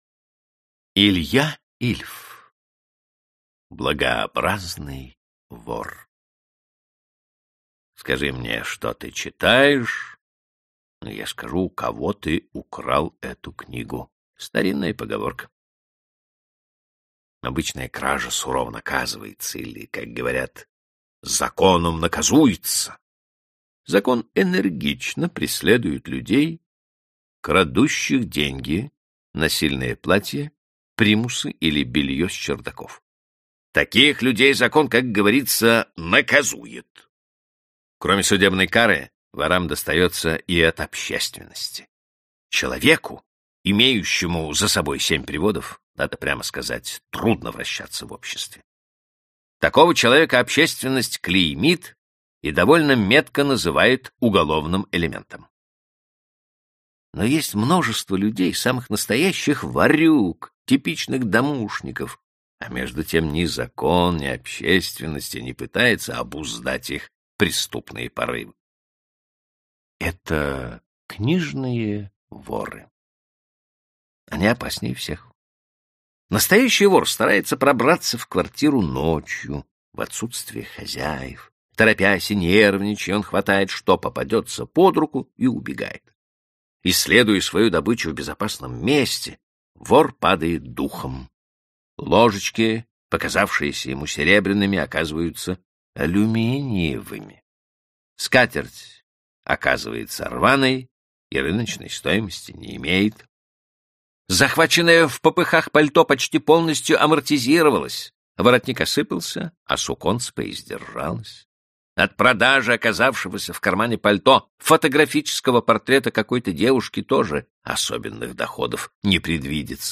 Аудиокнига Классика русского рассказа № 9 | Библиотека аудиокниг